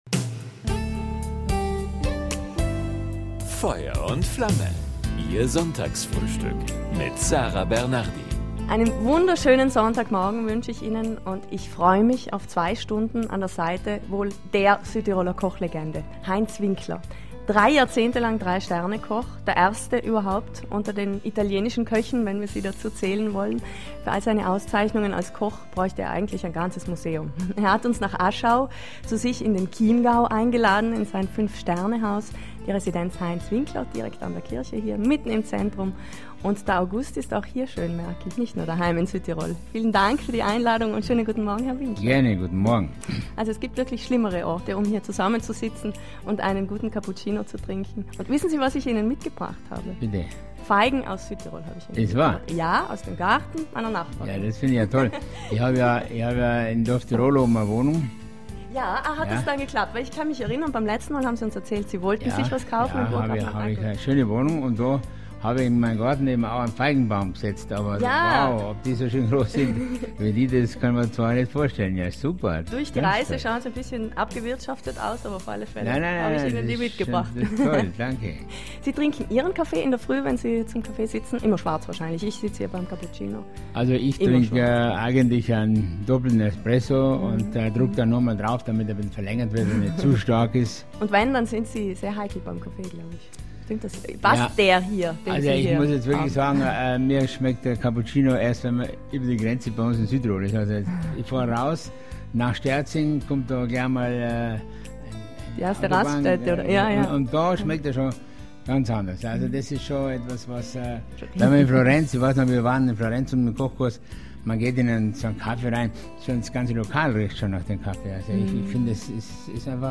Viel Gesprächsstoff für zwei Stunden Frühstücksratscher...